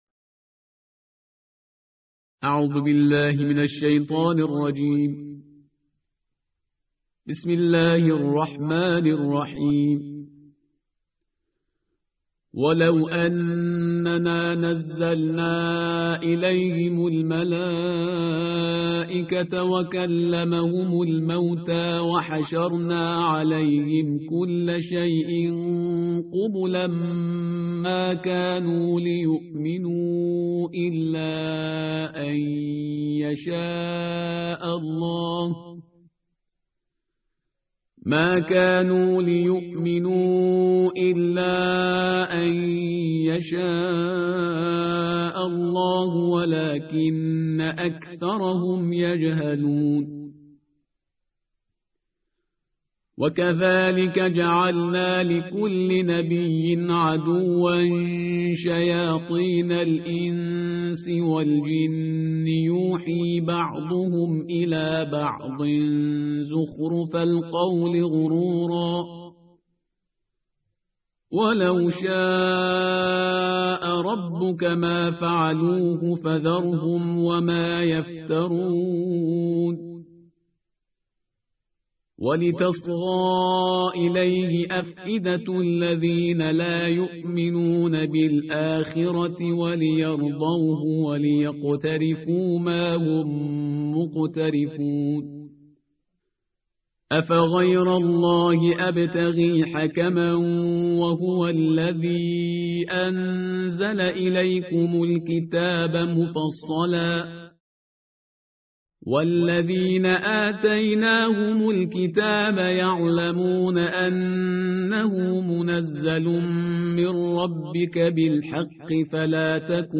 ترتیل جزءهشت قرآن کریم